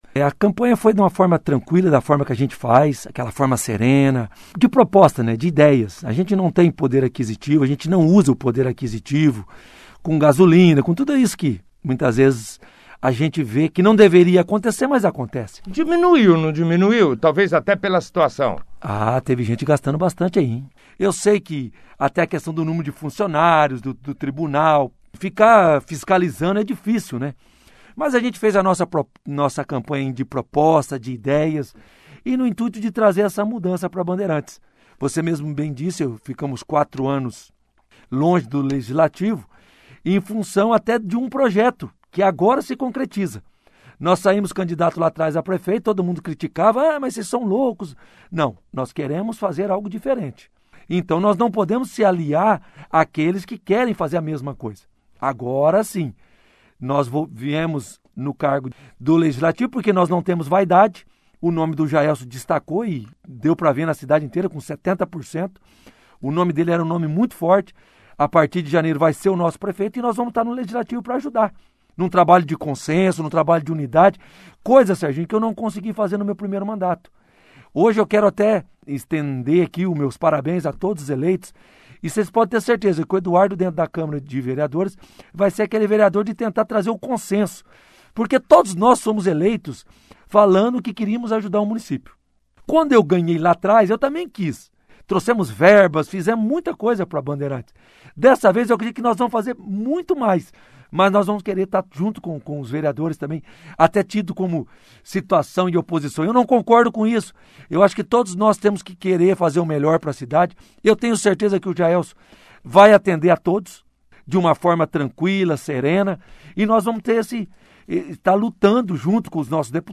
Candidato eleito para exercer o cargo de vereador no último dia 15 de novembro, pelo Podemos, com 425 votos, Dr. Eduardo José Serra do Espírito Santo, (foto), participou da 1ª edição do Jornal Operação Cidade, desta terça-feira, 01/12, falando sobre a expectativa de seu retorno a casa de leis, já que cumpriu um mandato de vereador entre 2012 a 2016 e a forma que pretende aproveitar a experiência adquirida no primeiro mandato para este que terá início em 01 de janeiro de 2020.